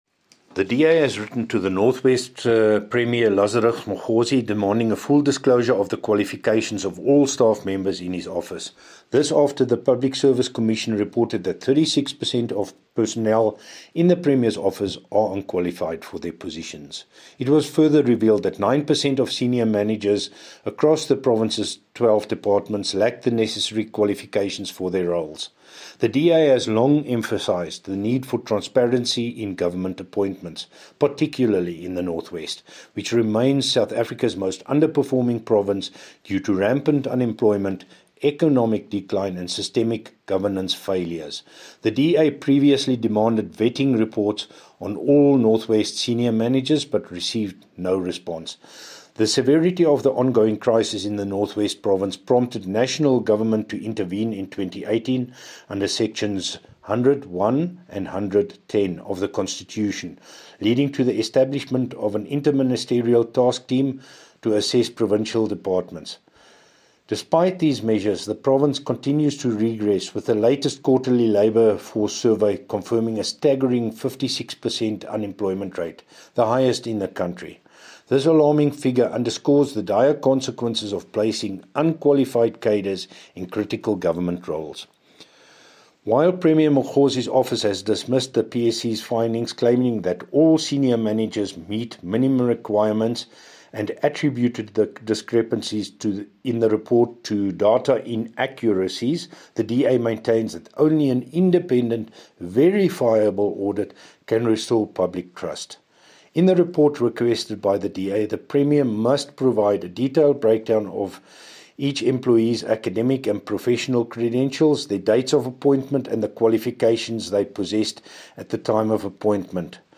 Issued by Wolfgang Wallhorn – DA Spokesperson on the Office of the Premier in the North West Provincial Legislature
Note to Broadcasters: Please find attached soundbites in